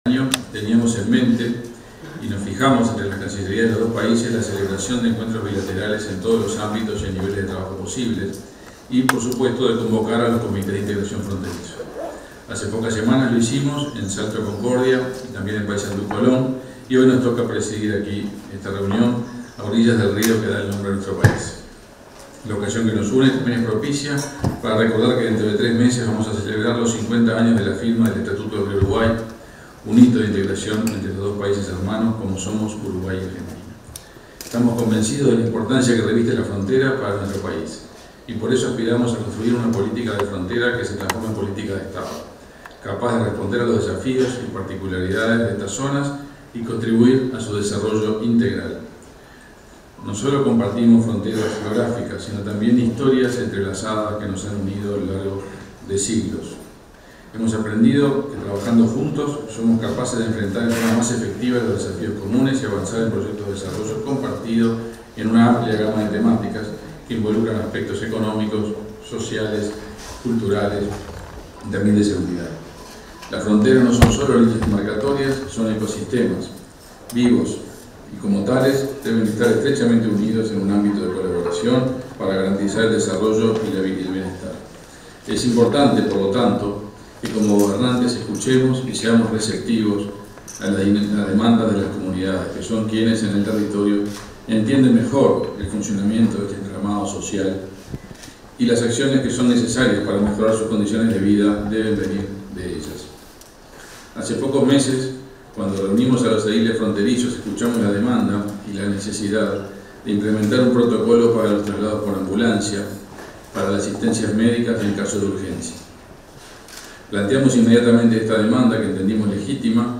Ceremonia de apertura de la reunión del Comité de Integración Fray Bentos - Gualeguaychú
Ceremonia de apertura de la reunión del Comité de Integración Fray Bentos - Gualeguaychú 03/12/2024 Compartir Facebook X Copiar enlace WhatsApp LinkedIn En la reunión del Comité de Integración Fray Bentos - Gualeguaychú, se expresaron el ministro de Relaciones Exteriores, Omar Paganini, y el vicecanciller argentino, Eduardo Bustamante.